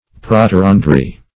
\Pro`ter*an"dry\